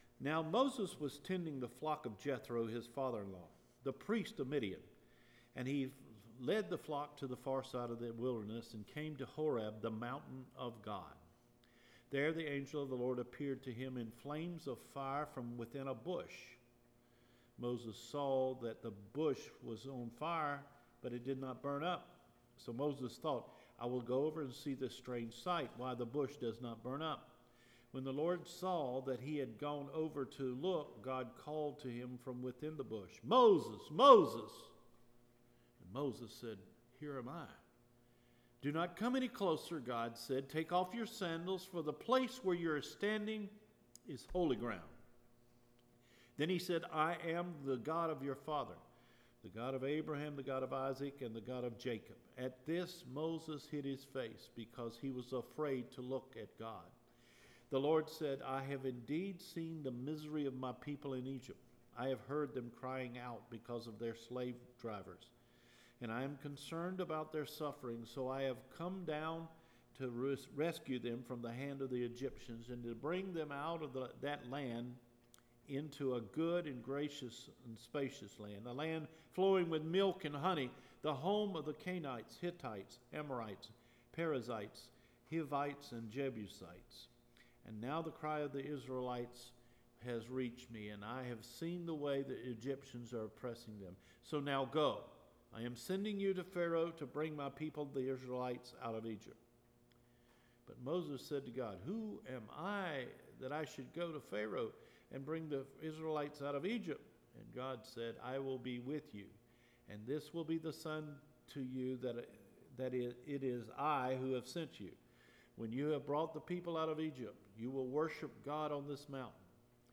SEPTEMBER 27 SERMON – “DO IT AGAIN, LORD”